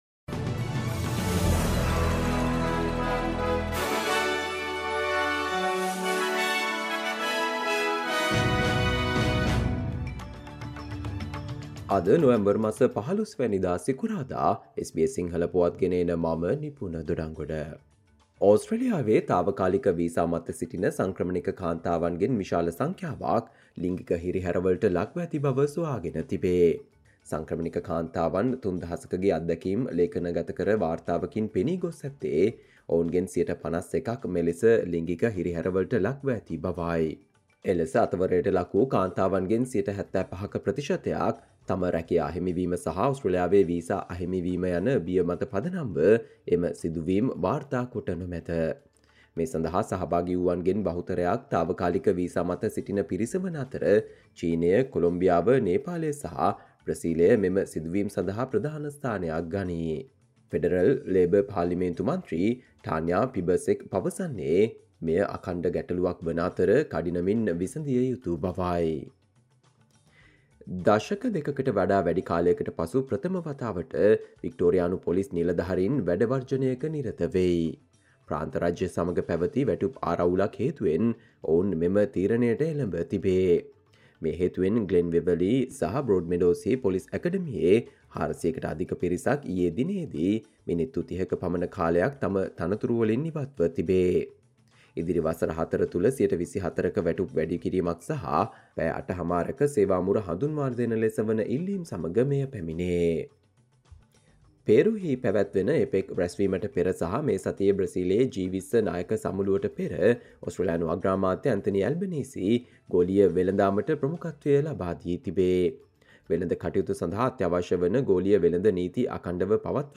Australian news in Sinhala, foreign and sports news in brief.